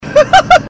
Laugh 29